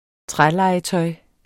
Udtale [ ˈtʁa- ]